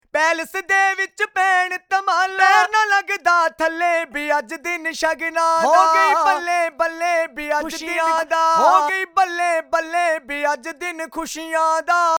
Key E Bpm 84